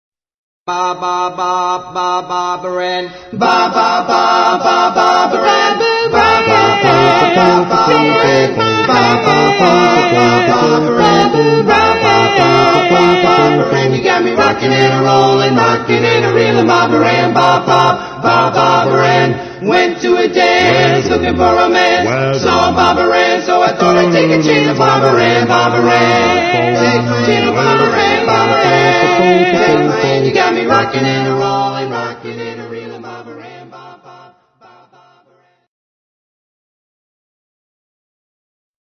authentic four-part harmonies